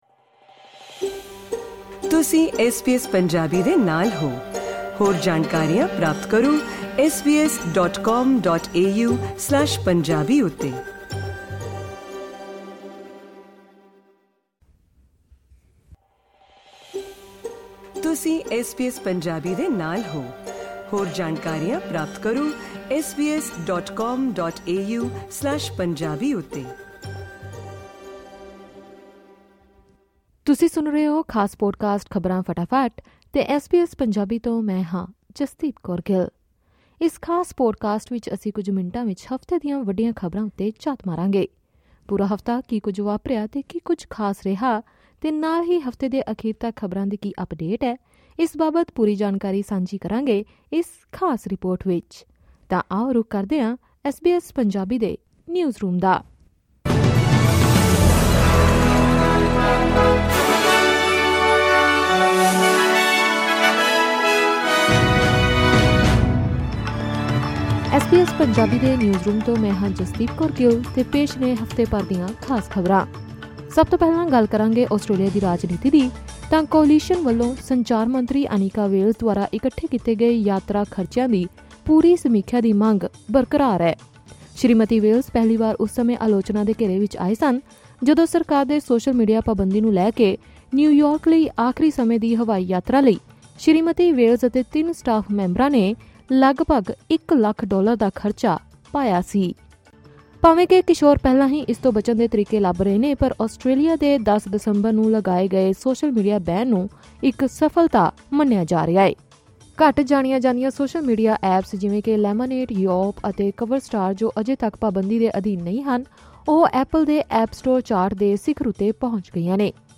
Weekly Wrap of News in Punjabi.
Punjabi Weekly News Wrap.